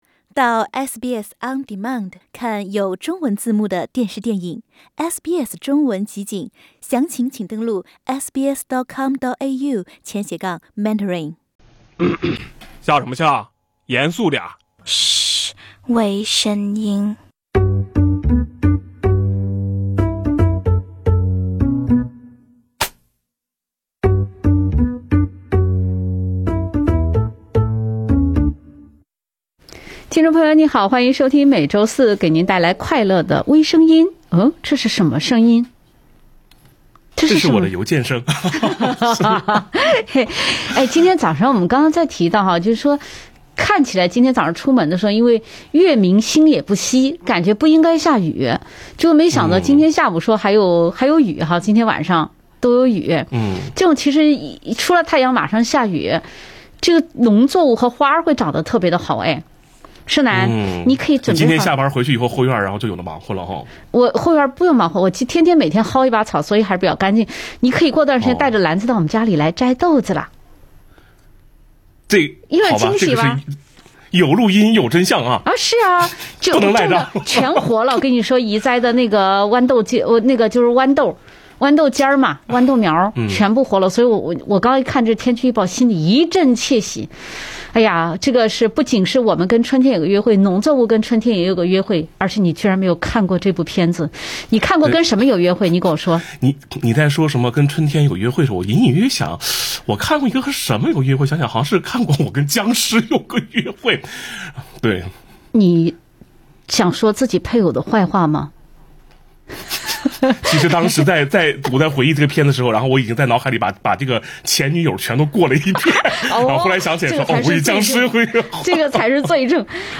有容乃大的最近释义，没眼看 德国马戏团疫情自谋出路，味儿有点大 另类轻松的播报方式，深入浅出的辛辣点评，更劲爆的消息，更欢乐的笑点，敬请收听每周四上午8点30分播出的时政娱乐节目《微声音》。